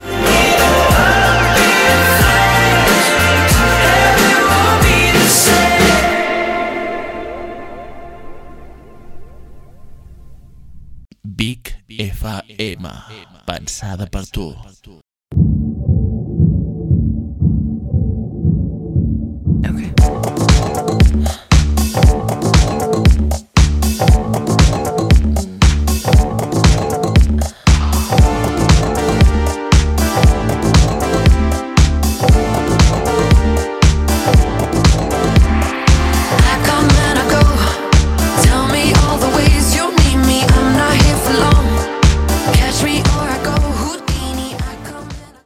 Tema musical, identificació de la ràdio i tema musical